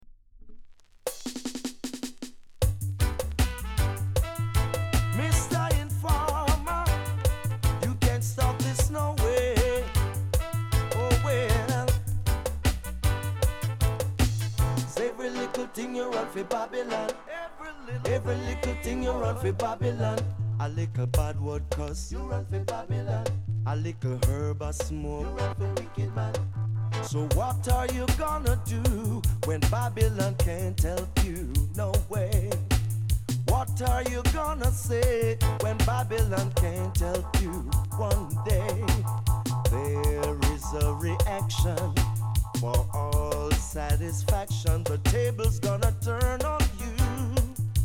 Home Discount Dancehall (Digital)